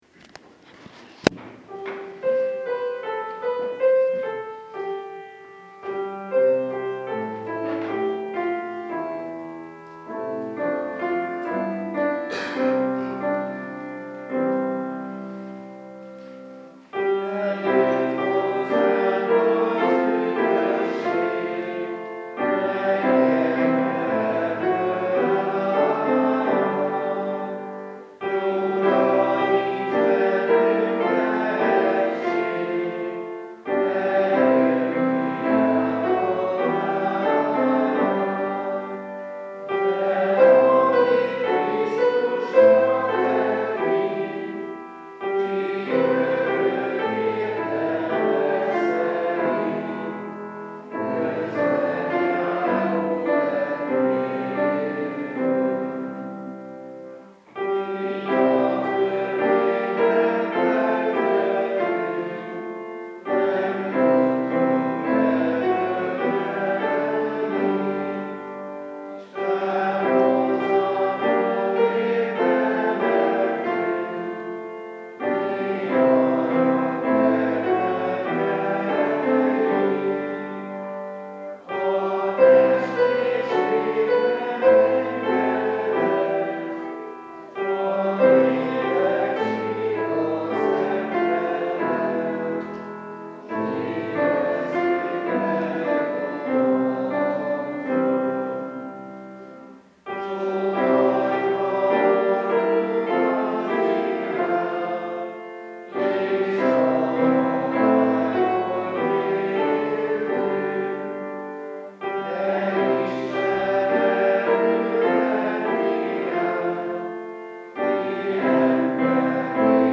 Ünnepi igehirdetések